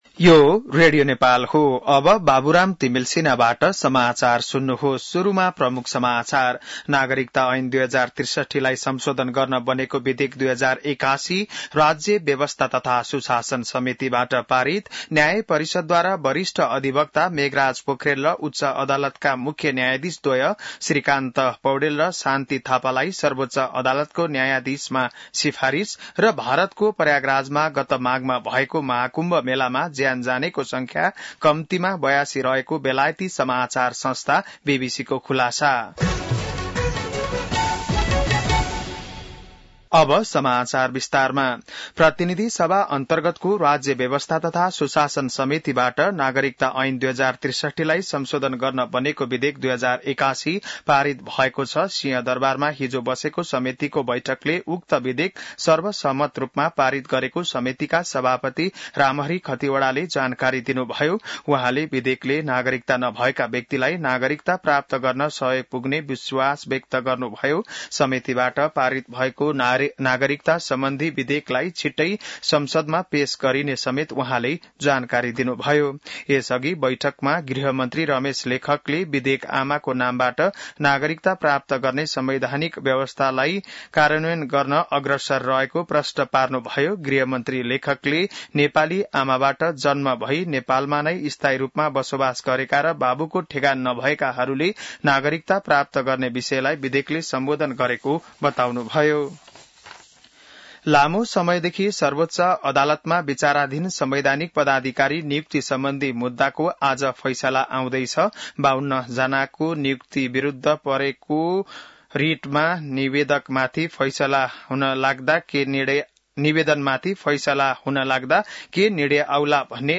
बिहान ९ बजेको नेपाली समाचार : २८ जेठ , २०८२